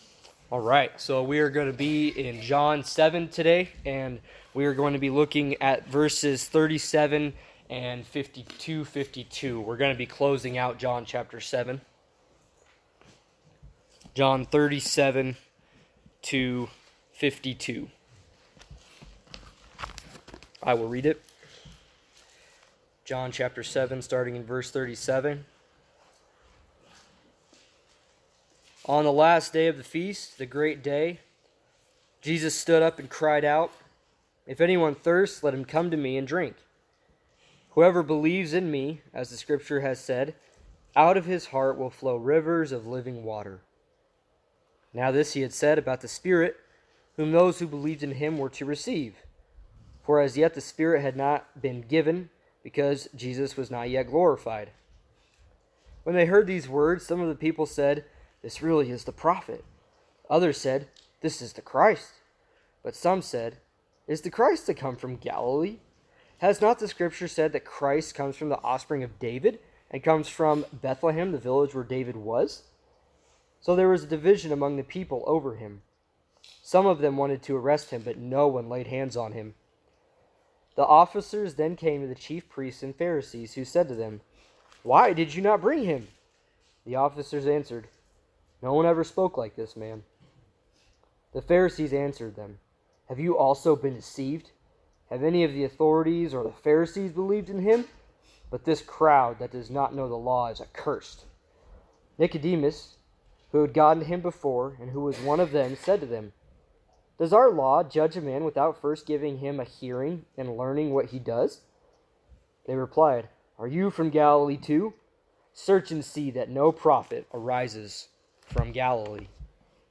John 7 Service Type: Sunday Studies Topics